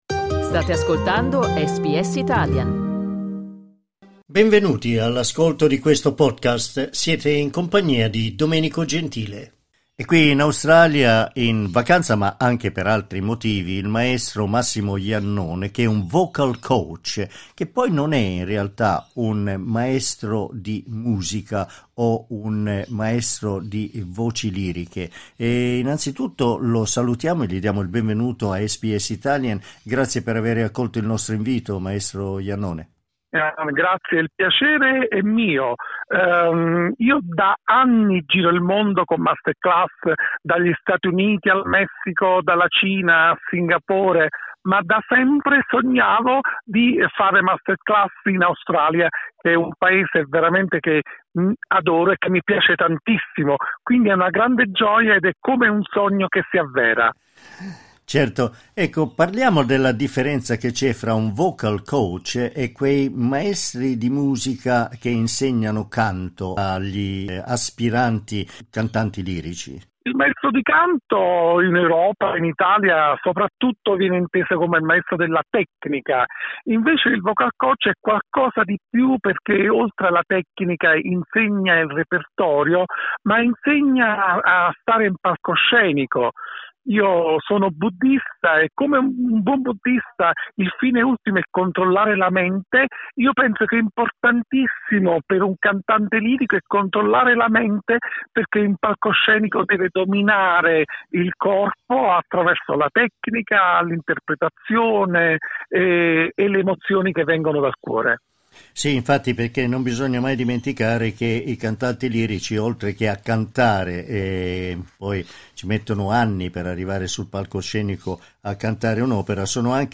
Clicca sul tasto "play" in alto per ascoltare l'intervista Ma che cos'è un vocal coach ?